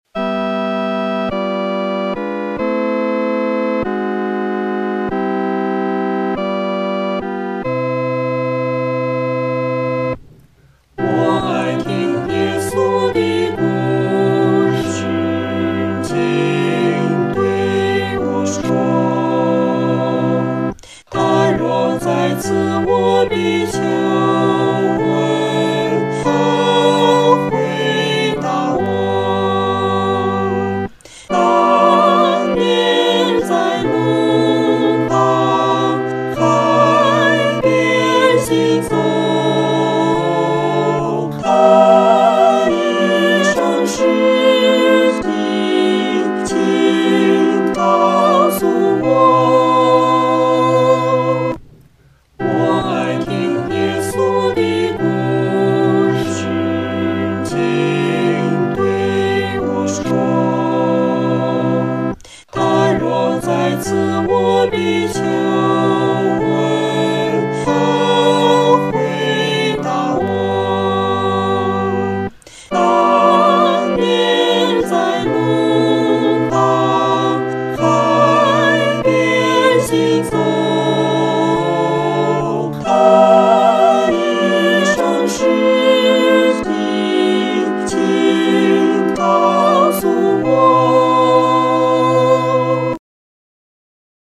合唱
四声